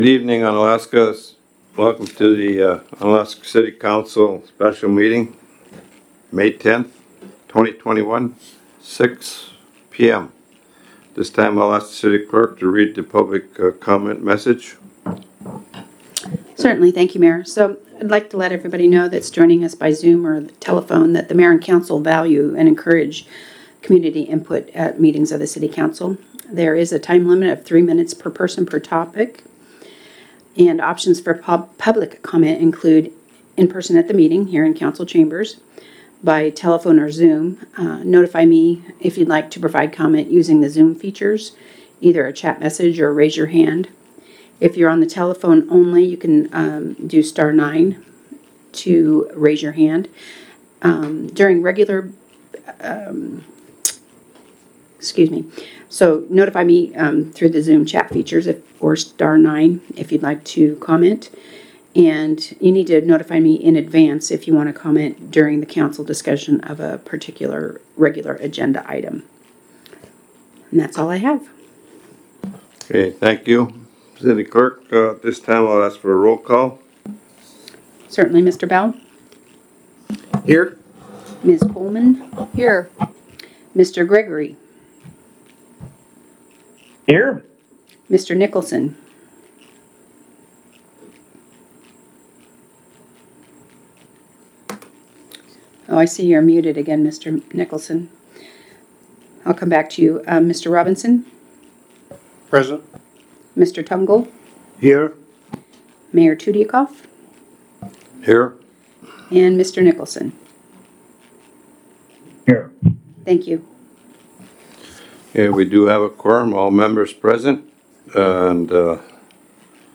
Special City Council Meeting - May 10, 2021 | City of Unalaska - International Port of Dutch Harbor